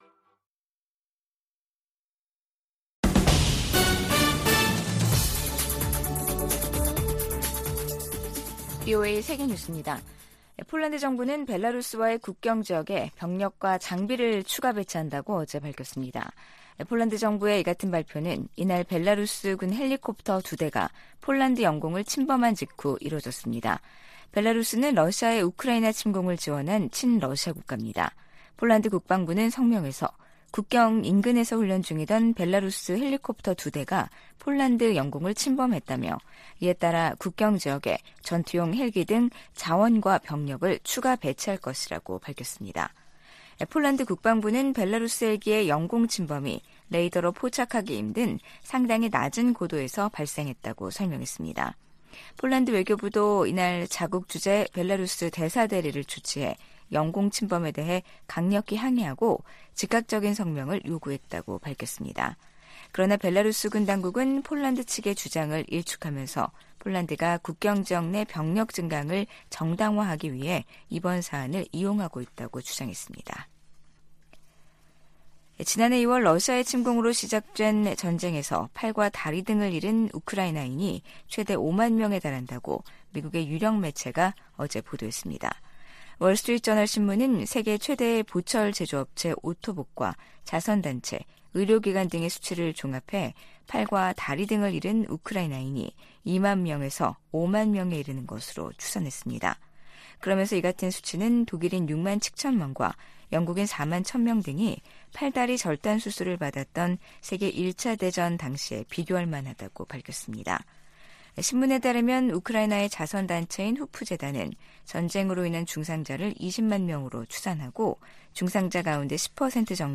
VOA 한국어 간판 뉴스 프로그램 '뉴스 투데이', 2023년 8월 2일 3부 방송입니다. 미국은 중국과 러시아가 북한의 행동을 규탄하는 데 동참할 수 있도록 계속 노력할 것이라고 린다 토머스-그린필드 유엔주재 미국대사가 밝혔습니다. 유럽연합(EU)이 북한과 러시아 간 무기 거래의 불법성을 지적하며 중단을 촉구했습니다. 미국 국방부가 미한일 3자 안보 협력 확대를 위해 노력하겠다는 입장을 거듭 확인했습니다.